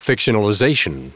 Prononciation du mot fictionalization en anglais (fichier audio)